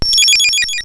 effect_thunderbolt.wav